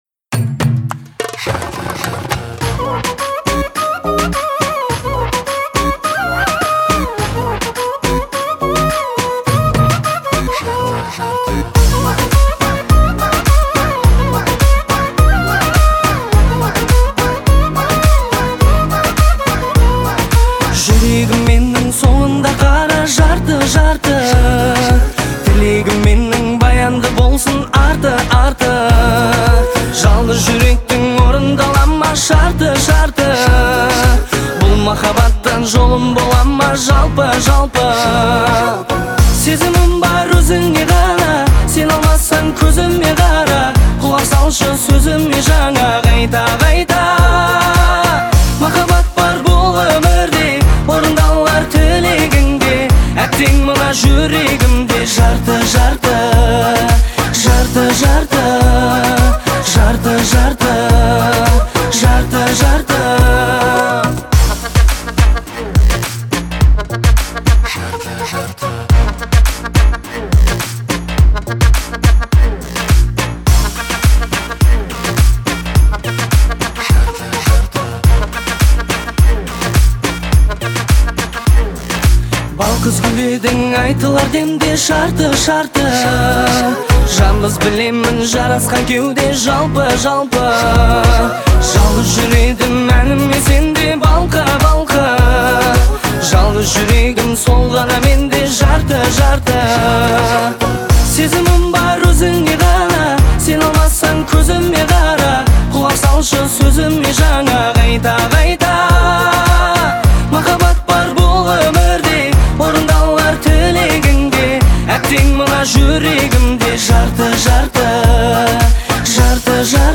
а также любителям лирических баллад.